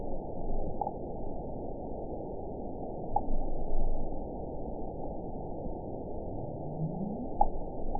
event 912523 date 03/28/22 time 20:32:16 GMT (3 years, 1 month ago) score 9.11 location TSS-AB03 detected by nrw target species NRW annotations +NRW Spectrogram: Frequency (kHz) vs. Time (s) audio not available .wav